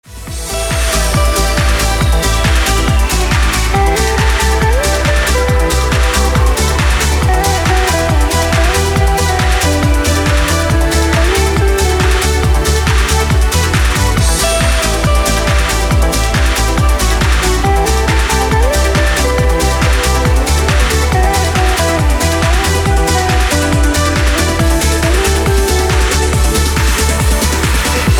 • Качество: 320, Stereo
красивые
dance
Electronic
электронная музыка
без слов
Trance